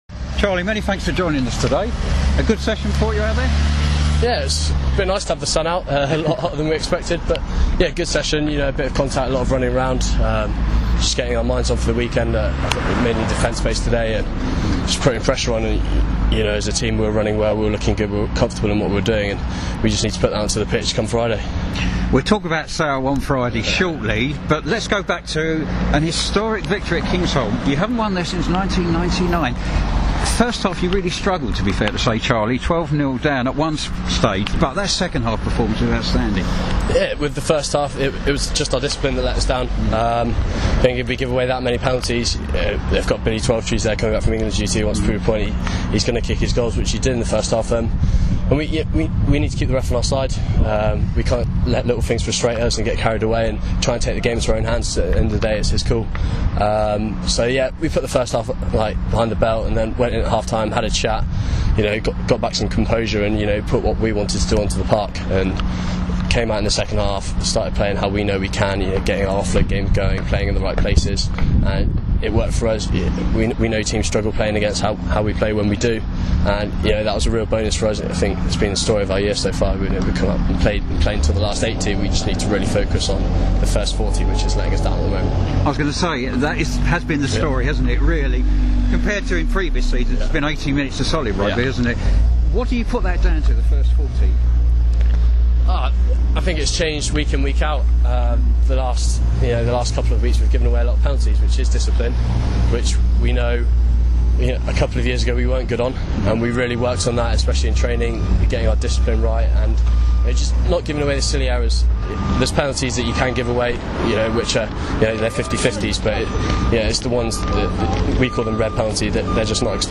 speaking ahead of Quins vs Sale